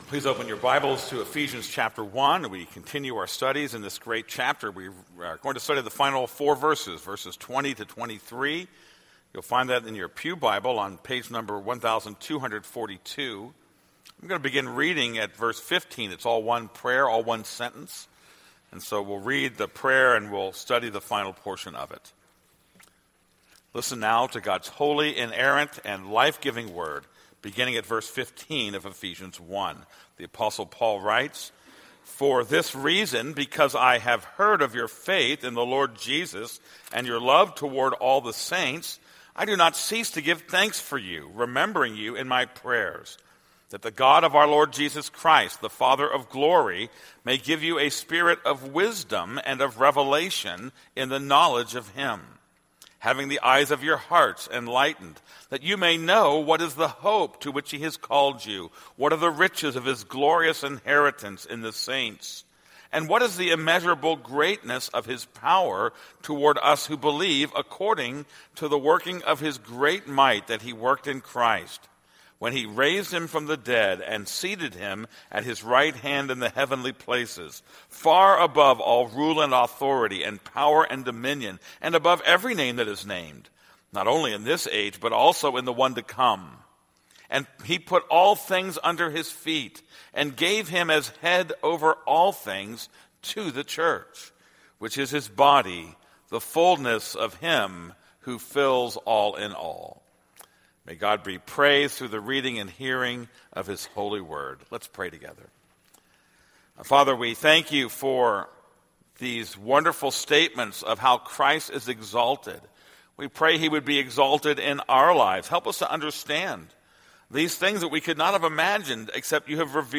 This is a sermon on Ephesians 1:20-23.